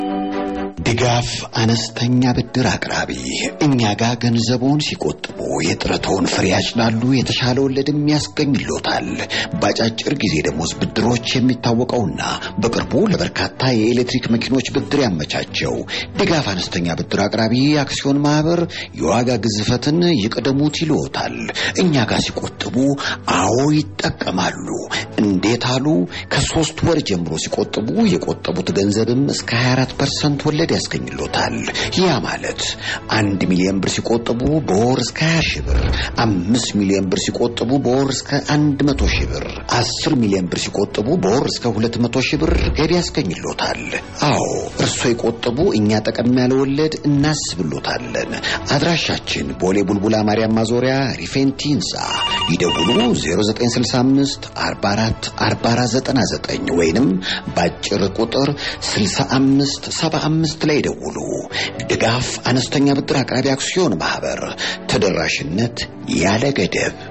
Brand: Degaf Microfinance Advert: Microfinance ad